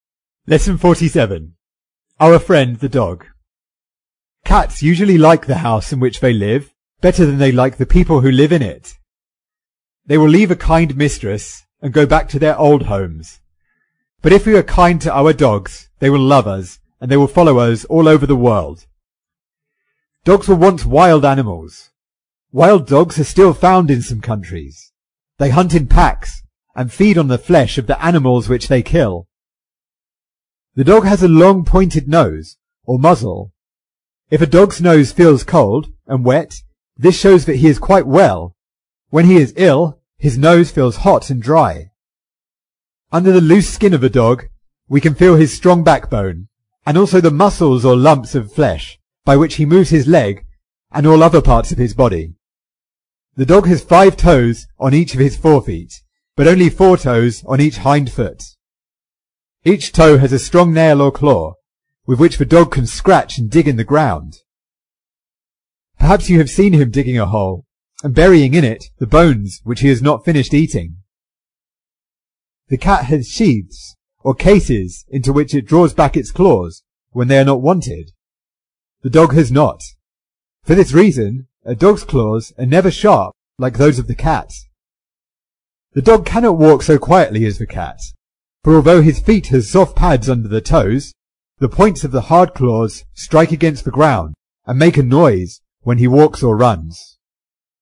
在线英语听力室英国学生科学读本 第47期:人类的朋友(1)的听力文件下载,《英国学生科学读本》讲述大自然中的动物、植物等广博的科学知识，犹如一部万物简史。在线英语听力室提供配套英文朗读与双语字幕，帮助读者全面提升英语阅读水平。